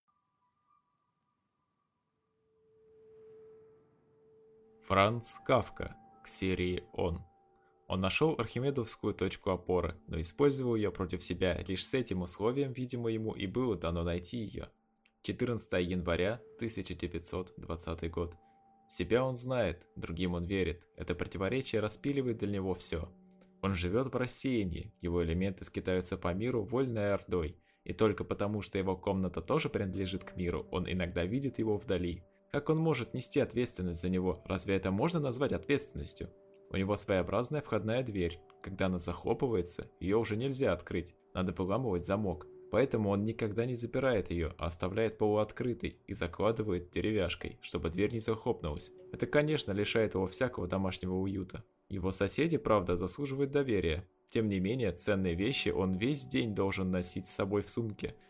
Аудиокнига К серии «Он» | Библиотека аудиокниг